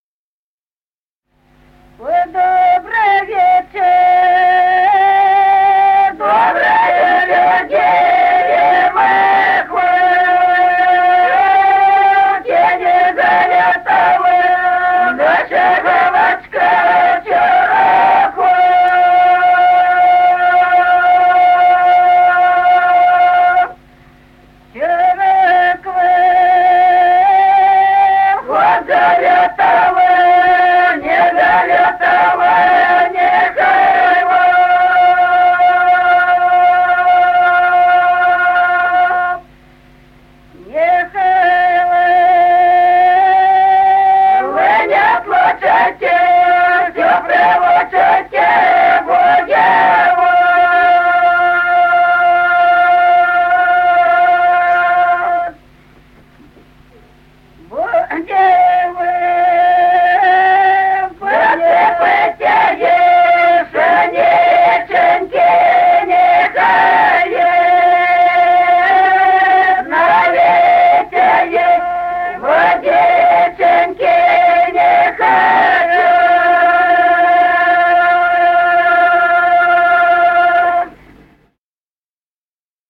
Народные песни Стародубского района «Ой, добрый вечер», свадебная.
запев
подголосник